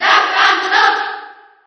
File:Duck Hunt Cheer Japanese SSB4.ogg